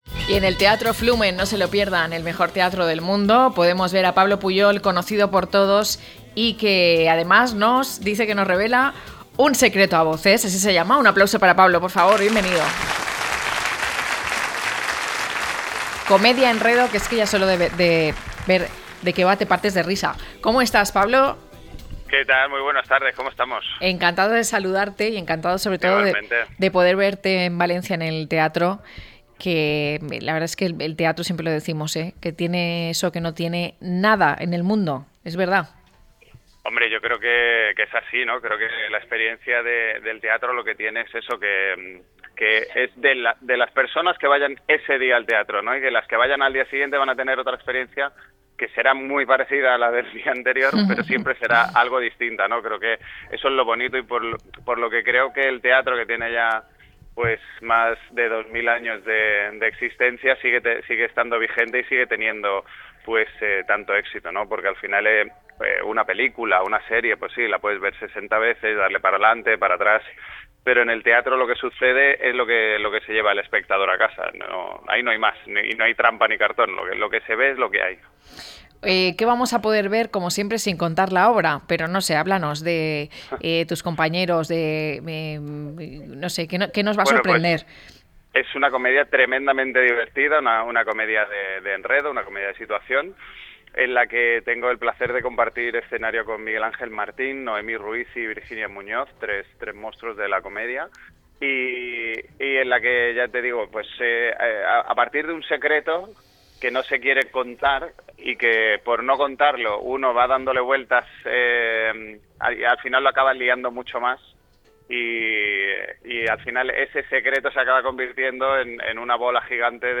Pablo Puyol, uno de los protagonistas de Un secreto a voces, la obra que aterriza el 19 de Octubre en el Teatro Flumen de Valencia, nos habla de esta comedia de enredo, así que, no se enreden en el enredo buscando lo que no hay.